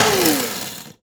Coffee_Grind04.wav